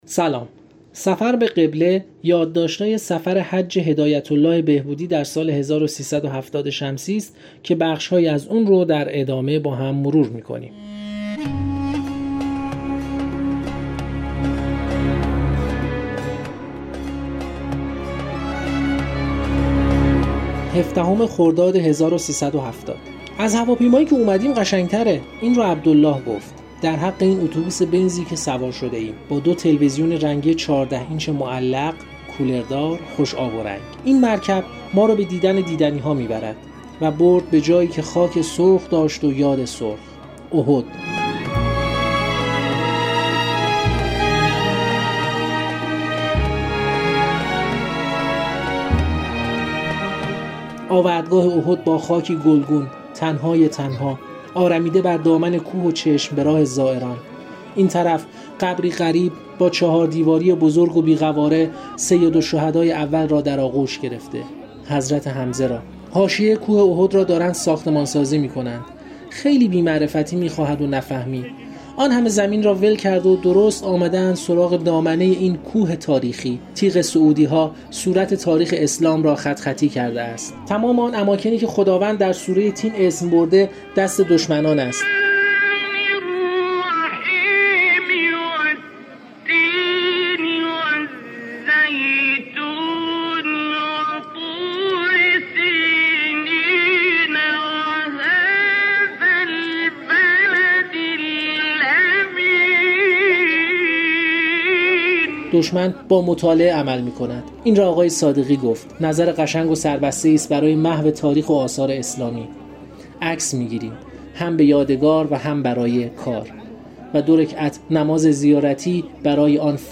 در یازدهمین قسمت از این مجموعه پادکست‌ها، بخش‌هایی کتاب «سفر به قبله» که سفرنامه حج هدایت‌الله بهبودی در سال۱۳۷۰ شمسی است را می‌شنویم.